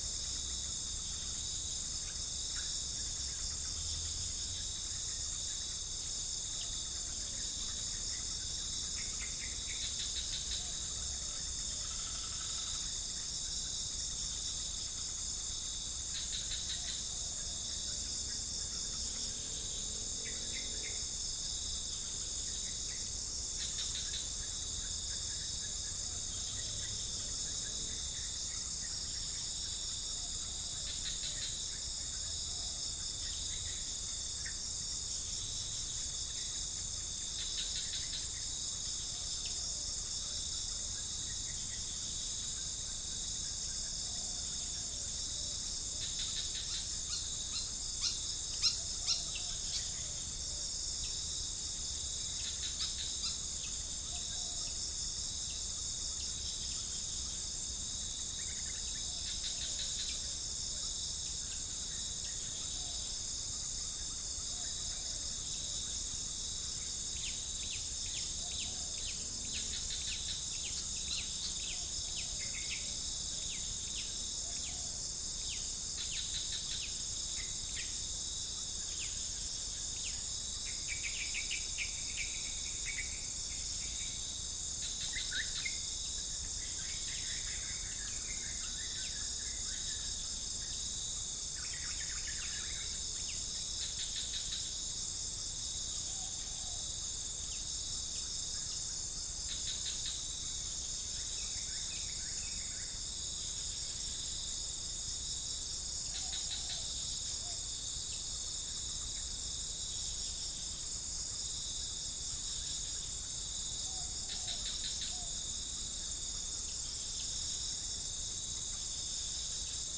Gallus gallus
Spilopelia chinensis
Pycnonotus goiavier
Orthotomus sericeus
Pycnonotus aurigaster
Todiramphus chloris
unknown bird
Rhipidura javanica
Orthotomus ruficeps